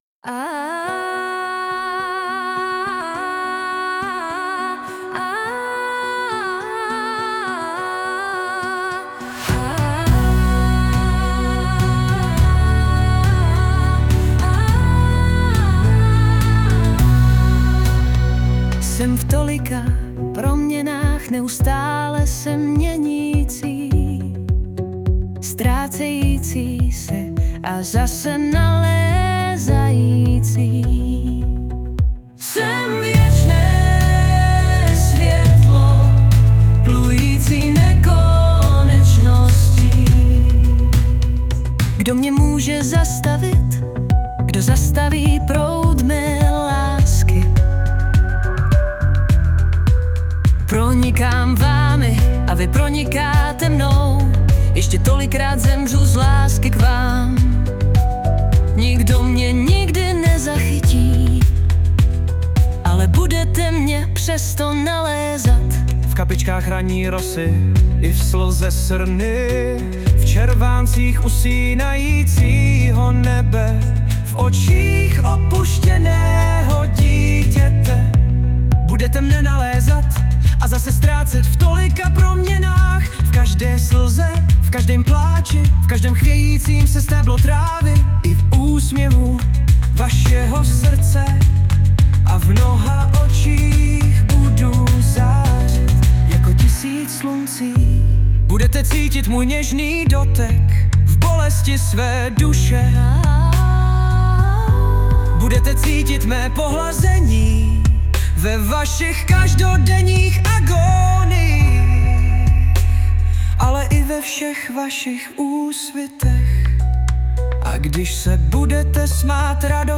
2012 & Hudba a Zpěv: AI